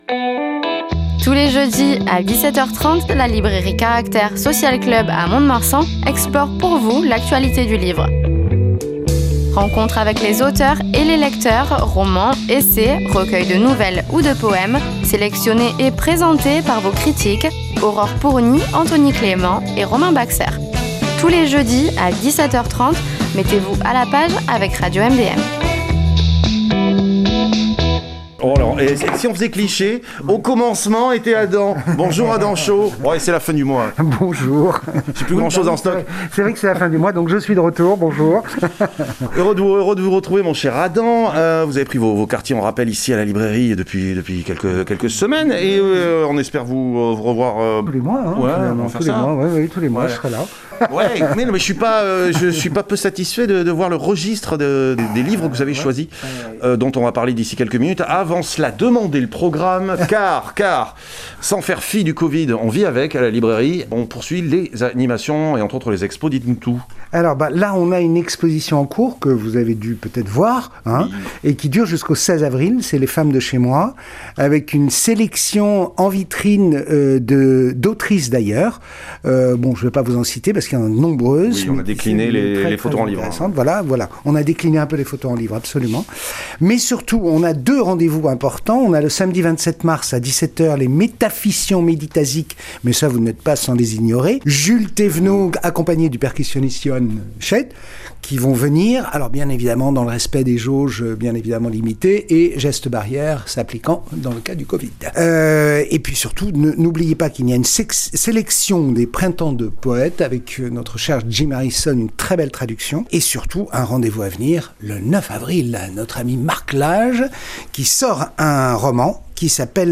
Chronique littéraire | L’ange déchu – Adultère – 25.03.2021